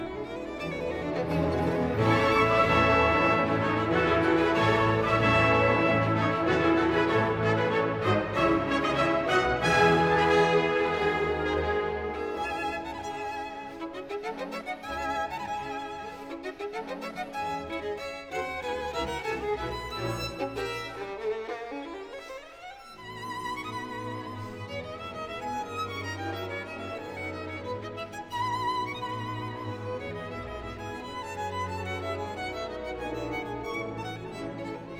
Струнные и рояль
Жанр: Классика